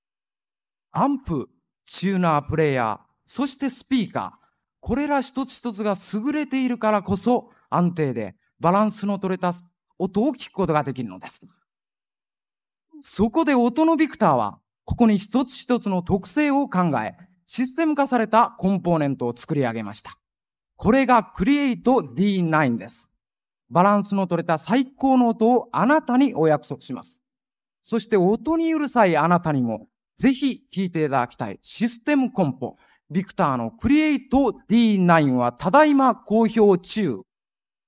1976年2月、高田馬場BIGBOXで開催されたDJ大会。
▶ DJ音声⑤（日本ビクター　クリエイトD9　商品紹介）
⑤DJの声-日本ビクターCM詳細内容-5.mp3